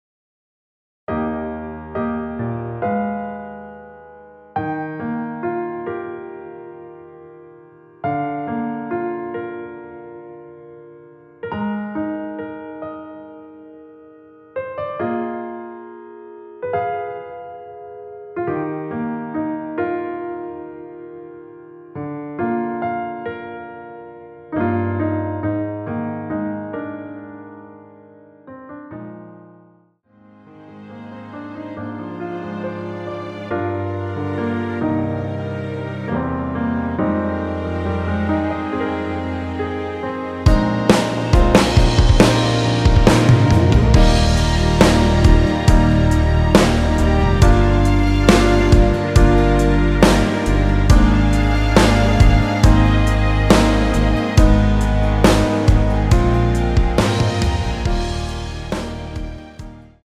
노래 들어가기 쉽게 전주 1마디 만들어 놓았습니다.(미리듣기 확인)
원키에서(-1)내린 (1절앞+후렴)으로 진행되는 MR입니다.
Eb
앞부분30초, 뒷부분30초씩 편집해서 올려 드리고 있습니다.